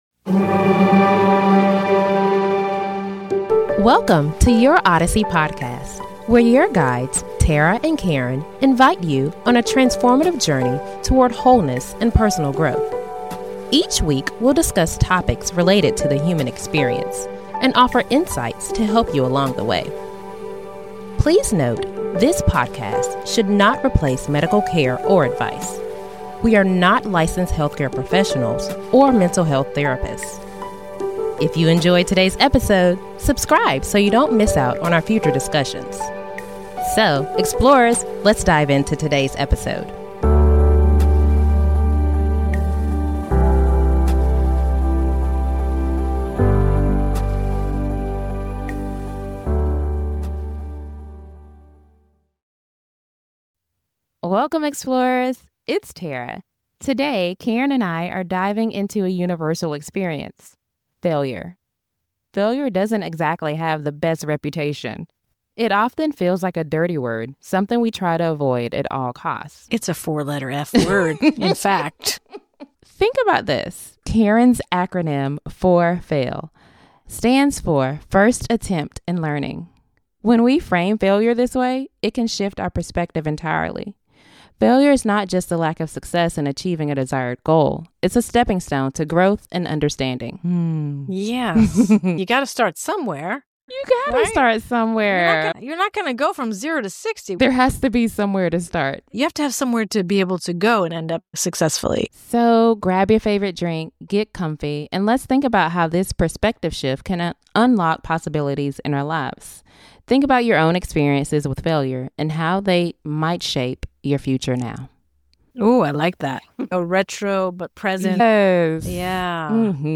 They discuss the societal stigma around failure, share personal stories, highlight famous individuals who turned their failures into stepping stones for success, and offer a four-step process for navigating failure. Tune in for an inspiring conversation that will empower you to embrace the importance of resilience, flexibility, and learning from setbacks and realize your growth potential.